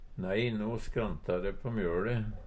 skrante - Numedalsmål (en-US)